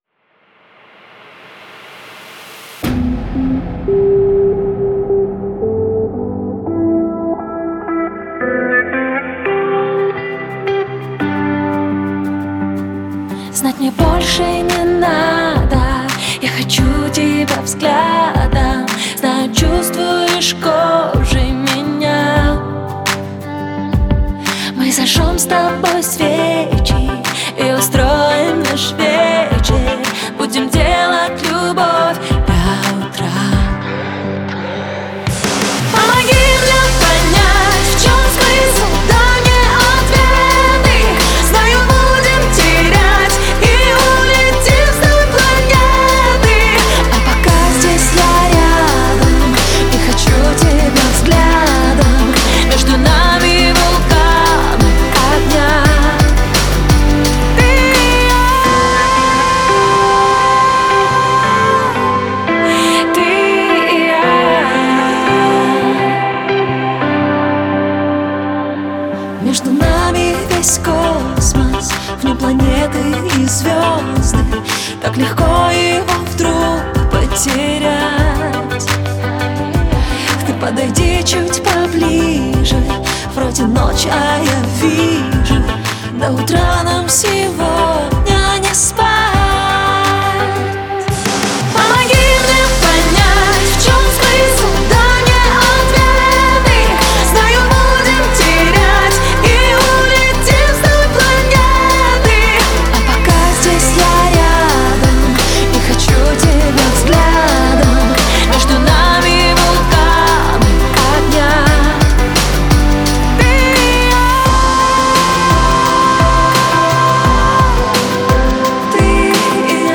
женский российский дуэт, существующий с августа 2018.
вокал, гитара
клавишные